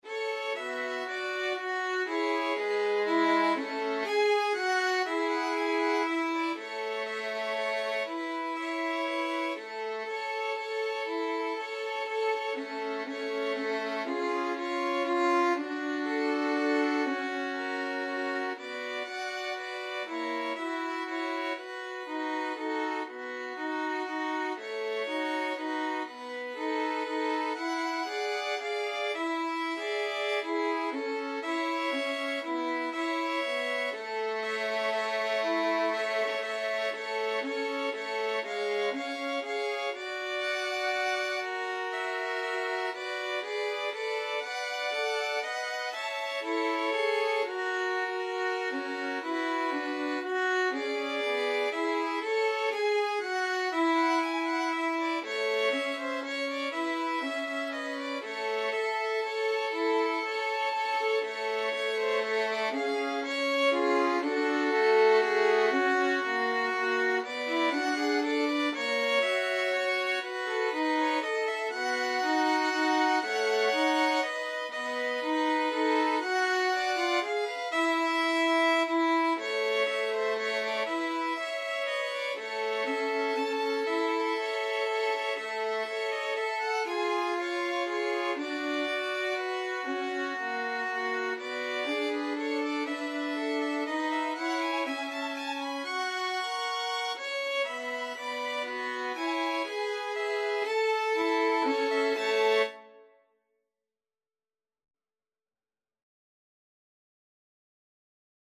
Free Sheet music for Violin Trio
A major (Sounding Pitch) (View more A major Music for Violin Trio )
= 120 Tempo di Valse = c. 120
3/4 (View more 3/4 Music)
Jazz (View more Jazz Violin Trio Music)